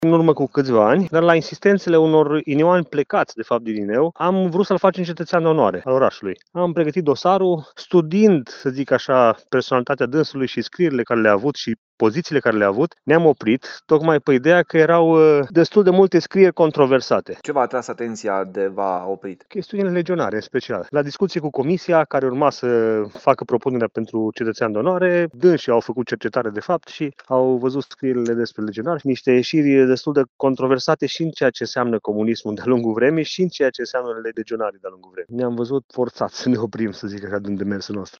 Primarul Călin Abrudan spune că, în momentul în care administrația locală a verificat activitatea lui Theodoru de-a lungul anilor, autoritățile au decis să nu îi mai acorde titlul.